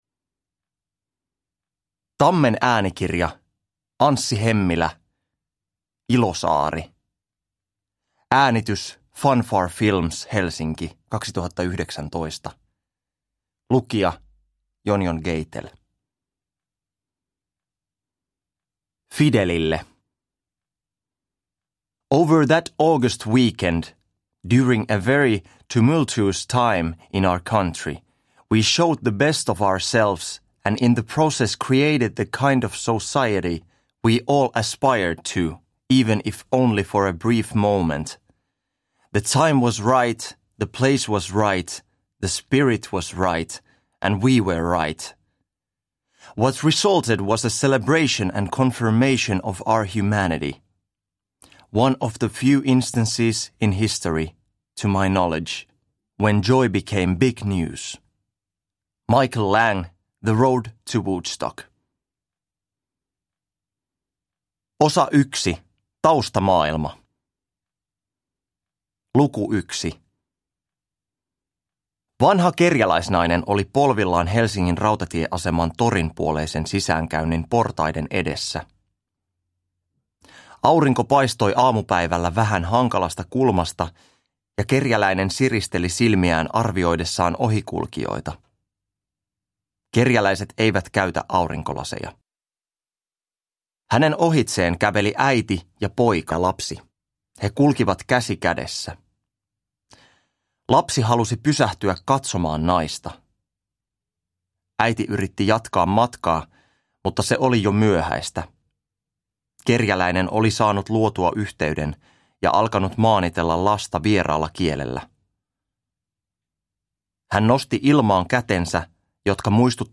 Ilosaari (ljudbok) av Anssi Hemmilä